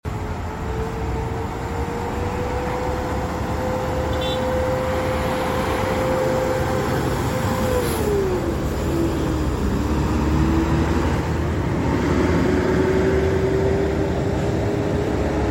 suara raungan mesin mercedes benz sound effects free download
suara raungan mesin mercedes benz 1626